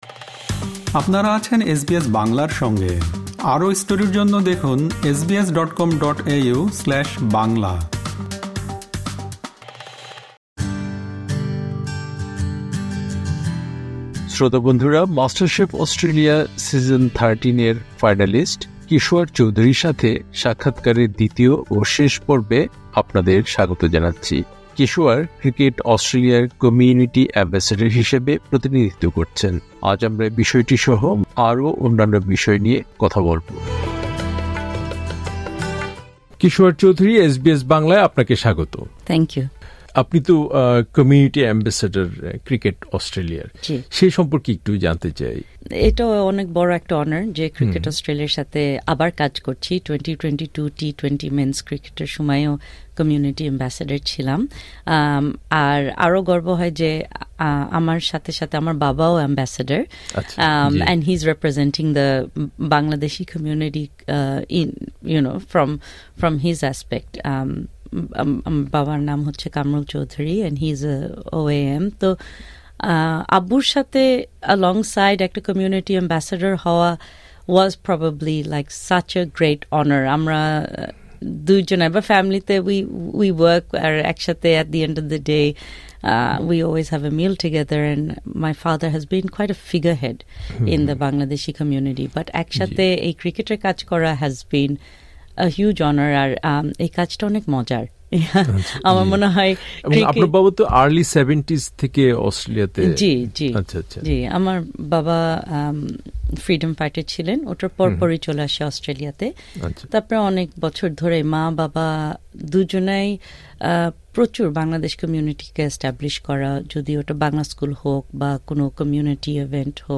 মাস্টারশেফ অস্ট্রেলিয়া সিজন থার্টিন-এর ফাইনালিস্ট কিশোয়ার চৌধুরী ক্রিকেট অস্ট্রেলিয়ার কমিউনিটি এম্বেসেডার হিসেবে প্রতিনিধিত্ব করছেন। তার সাথে সাক্ষাৎকারের দ্বিতীয় ও শেষ পর্বে এ বিষয়টি সহ মাস্টারশেফ পরবর্তী আরো অন্যান্য বিষয় নিয়ে কথা বলেছেন কিশোয়ার।